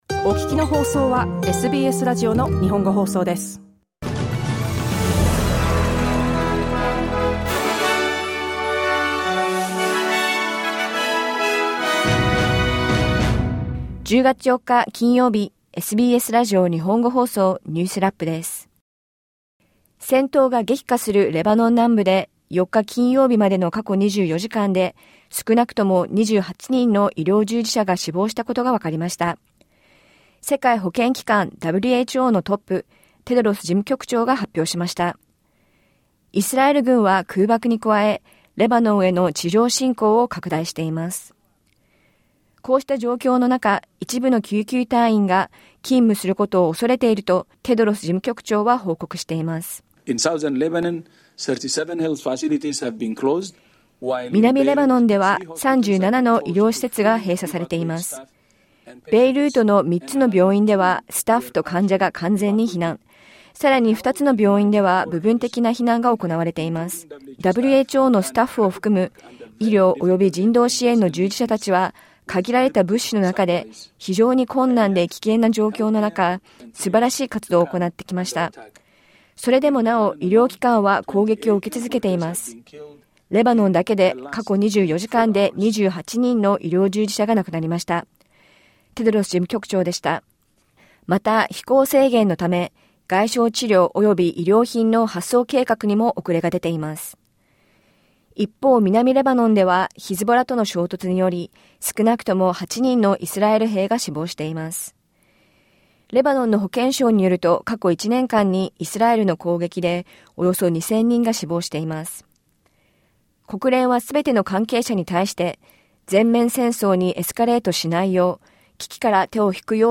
SBS日本語放送週間ニュースラップ 10月4日金曜日
世界保健機関（WHO）は、イスラエルの侵攻が続く中、レバノンで少なくとも28人の医療従事者が死亡したと報告しています。1週間を振り返る週間ニュースラップです。